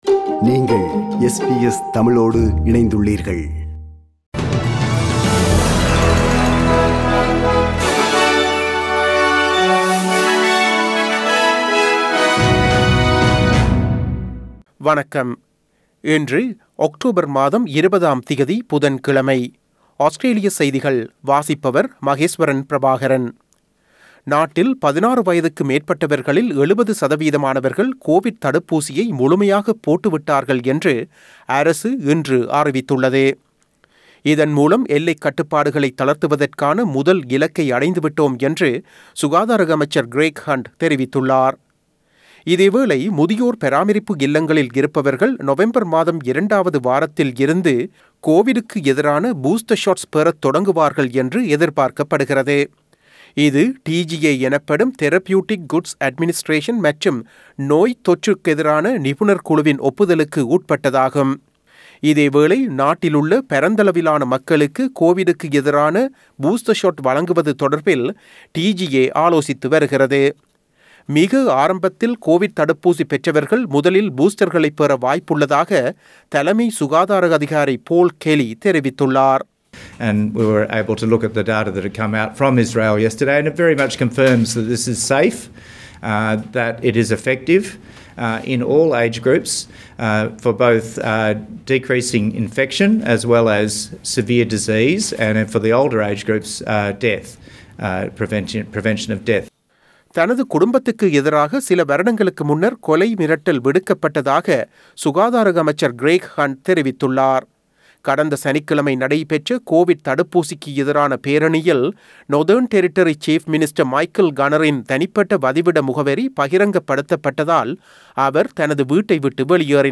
Australian news bulletin for Wednesday 20 October 2021.